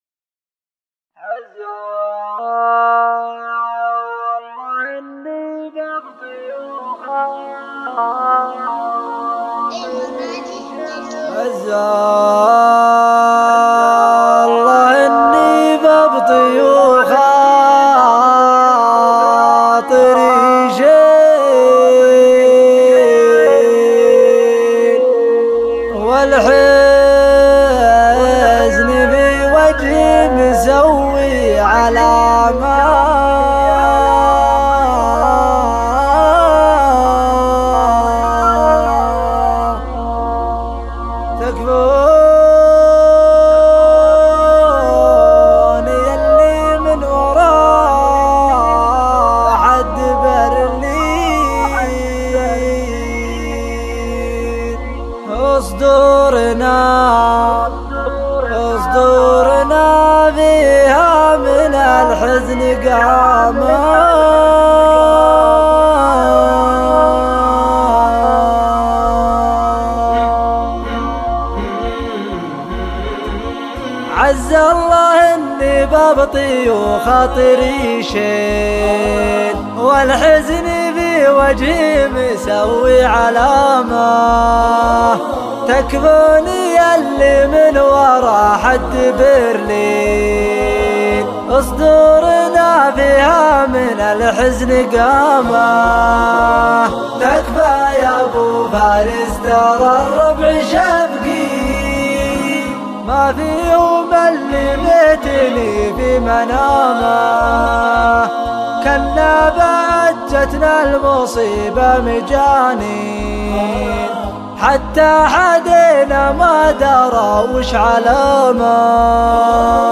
مرثيه